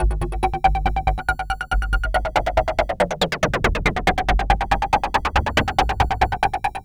tx_synth_140_paradid_EMin2.wav